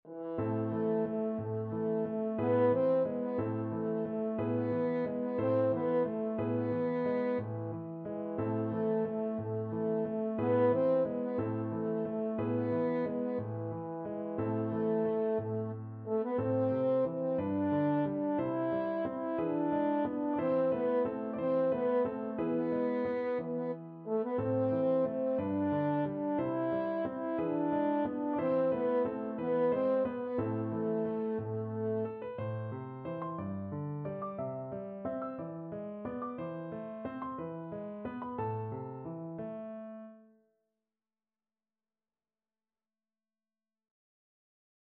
French Horn
6/8 (View more 6/8 Music)
A minor (Sounding Pitch) E minor (French Horn in F) (View more A minor Music for French Horn )
~ = 90 Munter
Classical (View more Classical French Horn Music)